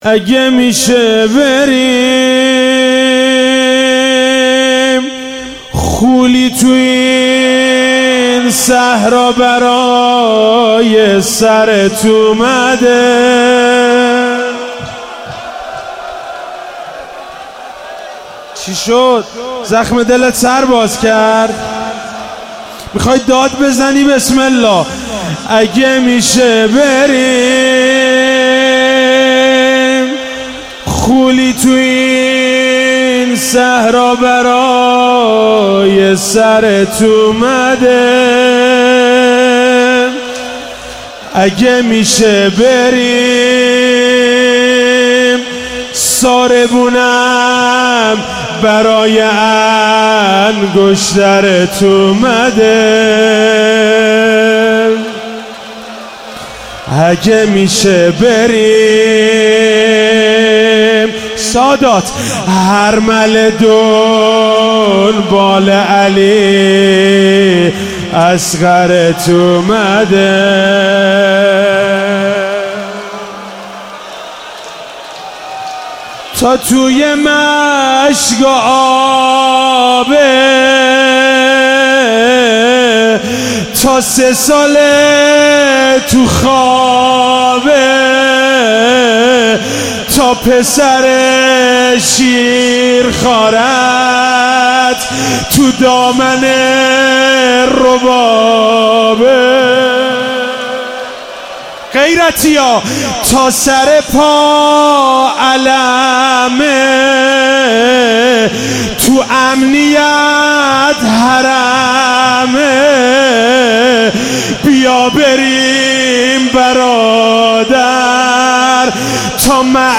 شب دوم محرم 95_روضه_ اگه میشه بریم خولی تو این صحرا